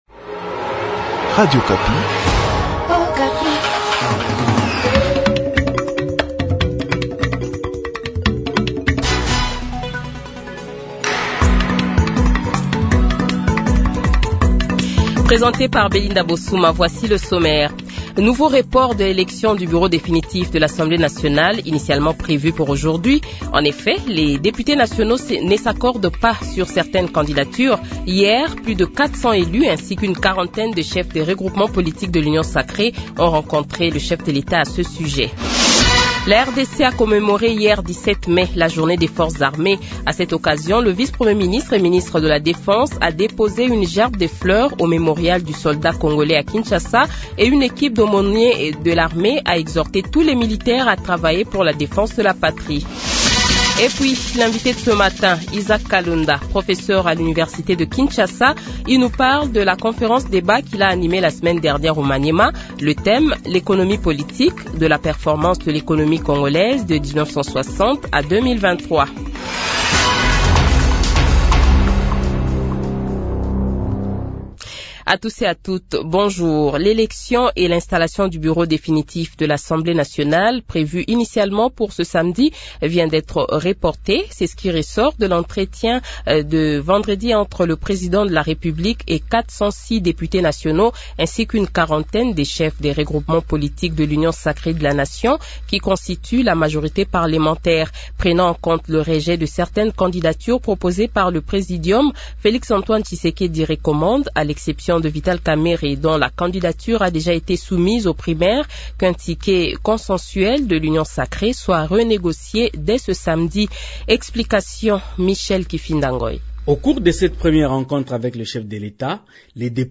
Journal Francais Matin
Le Journal de 7h, 18 Mai 2024 :